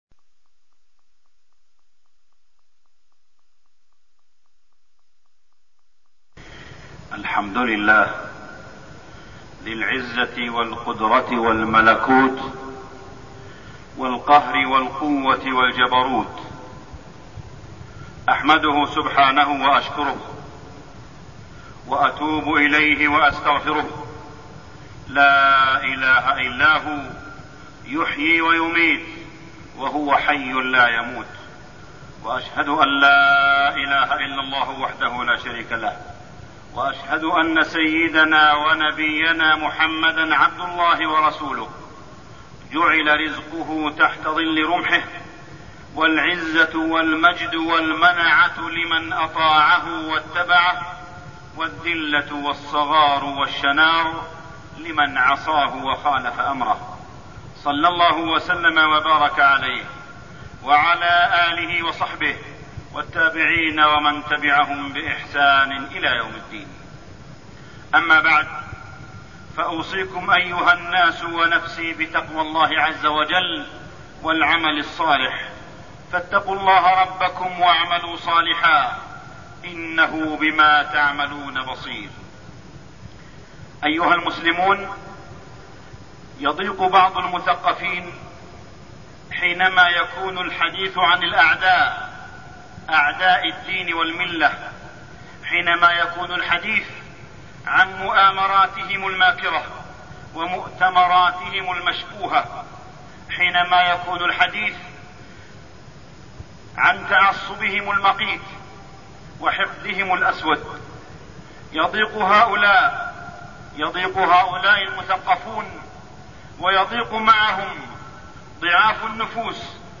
تاريخ النشر ٢٣ صفر ١٤١٦ هـ المكان: المسجد الحرام الشيخ: معالي الشيخ أ.د. صالح بن عبدالله بن حميد معالي الشيخ أ.د. صالح بن عبدالله بن حميد قضية البوسنة والهرسك